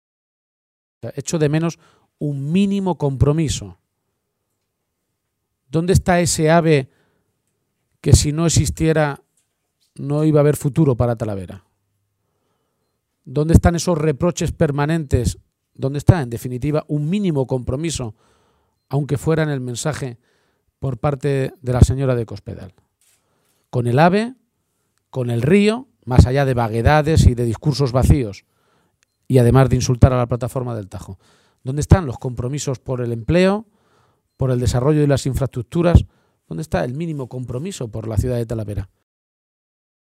La dirección regional del Partido Socialista de Castilla-La Mancha se ha reunido esta tarde en Talavera de la Reina.